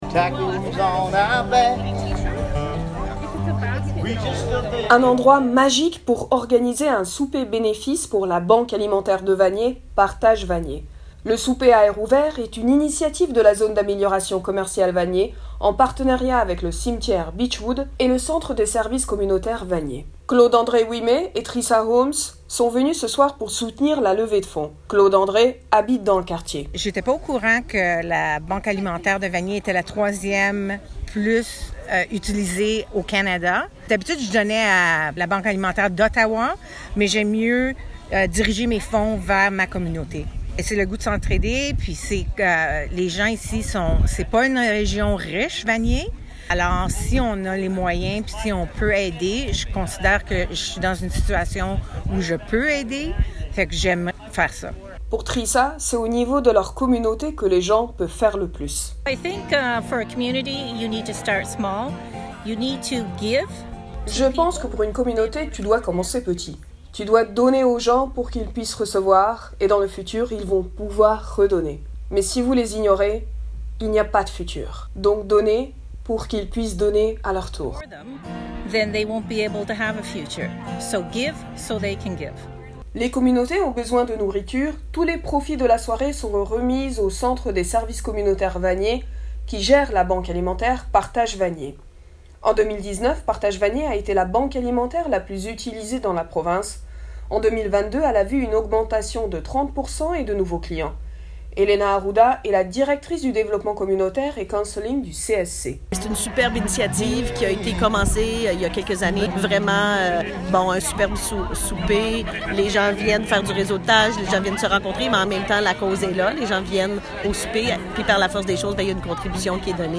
Reportage-ZAC-vanier-IJL.mp3